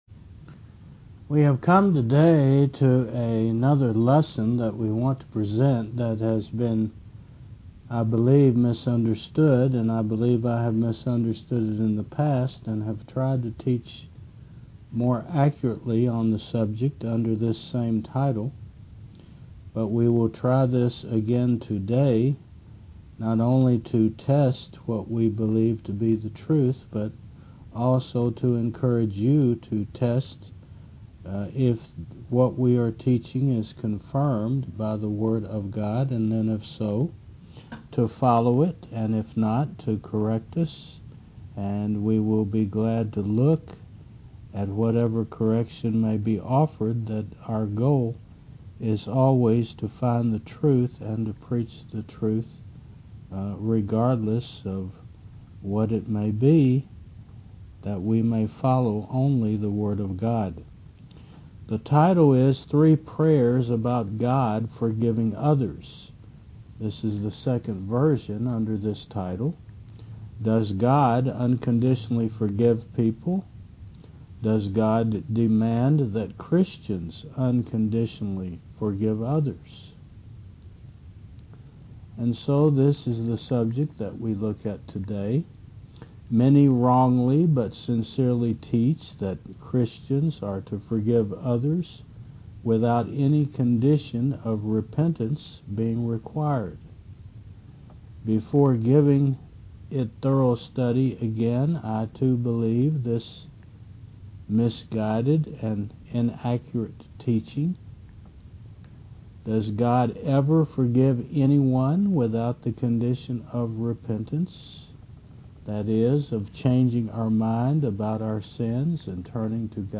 Faith Service Type: Mon. 9 AM We are told by many that a Christian must forgive all no matter if someone repents or not.